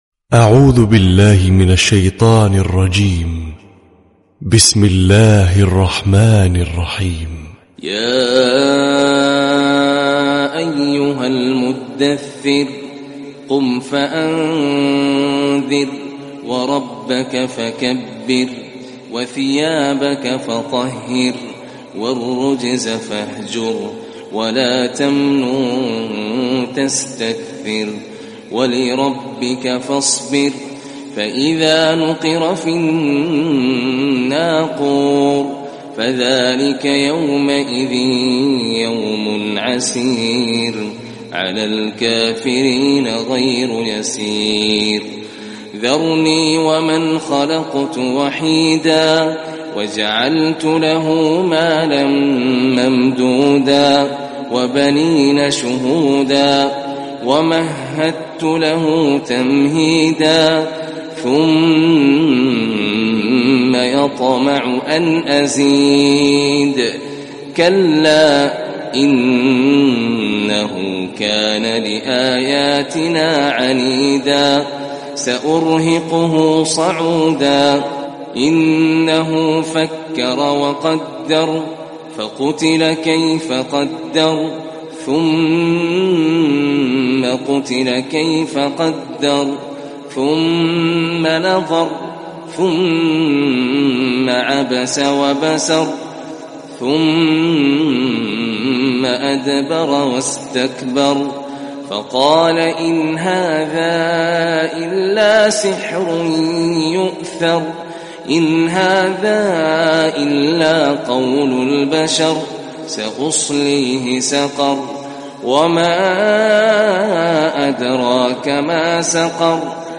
🕋🌻•تلاوة صباحية•🌻🕋